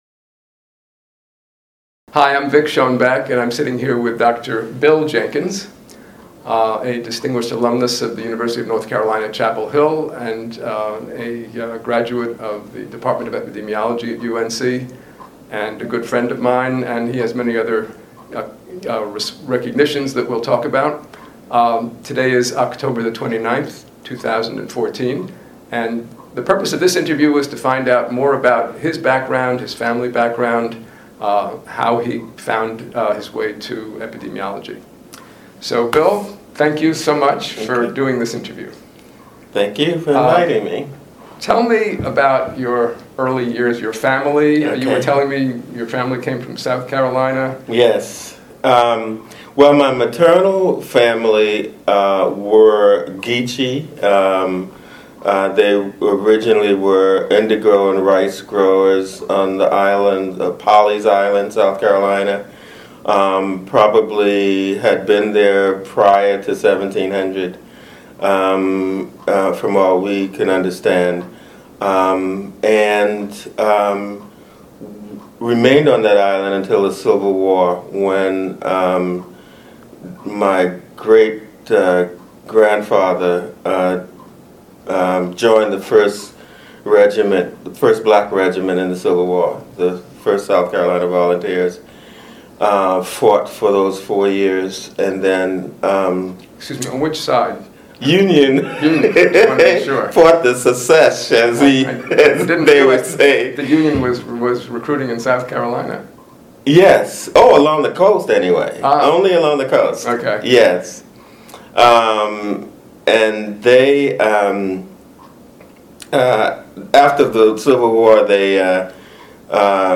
Interview
in the John Cassel Conference Room, Department of Epidemiology, UNC Gillings School of Global Public Health